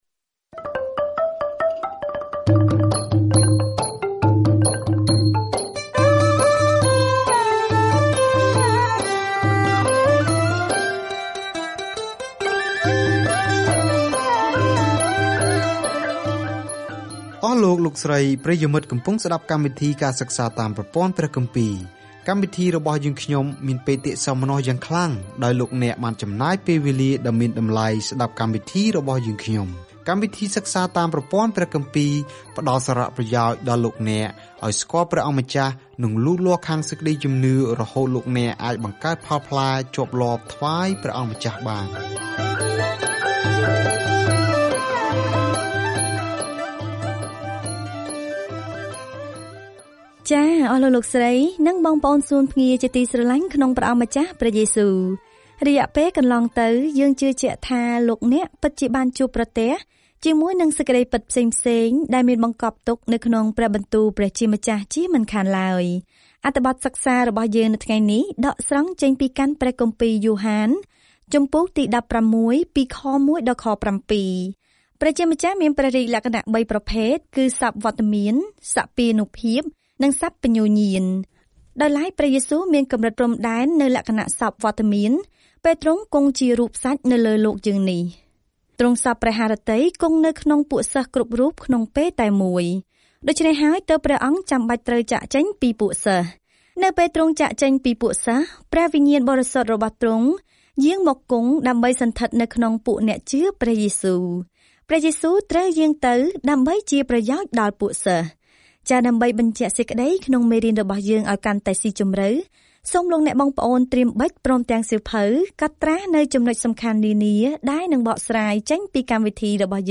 ដំណឹងល្អដែលយ៉ូហានពន្យល់គឺប្លែកពីសៀវភៅដំណឹងល្អដទៃទៀត ហើយផ្ដោតលើមូលហេតុដែលយើងគួរជឿលើព្រះយេស៊ូវគ្រីស្ទ និងរបៀបមានជីវិតក្នុងព្រះនាមនេះ។ ការធ្វើដំណើរជារៀងរាល់ថ្ងៃតាមរយៈយ៉ូហាន នៅពេលអ្នកស្តាប់ការសិក្សាជាសំឡេង ហើយអានខគម្ពីរដែលជ្រើសរើសពីព្រះបន្ទូលរបស់ព្រះ។